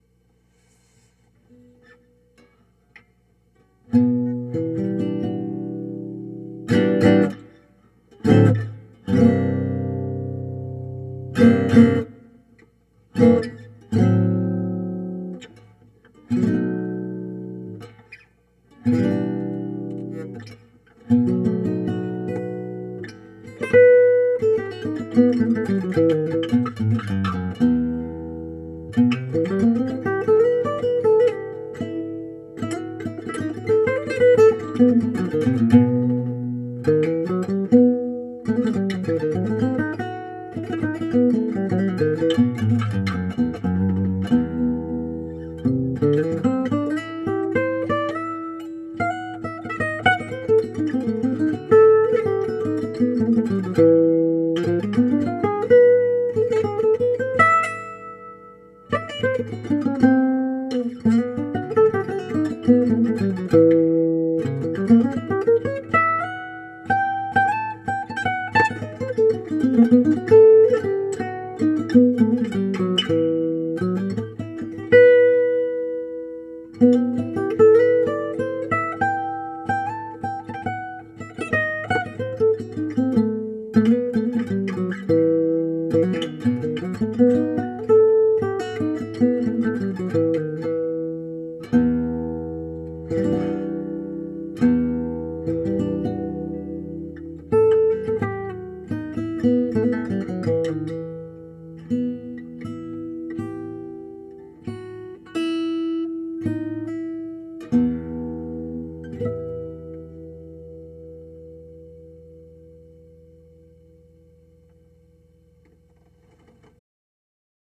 It definitely sounds more like a mic on a guitar.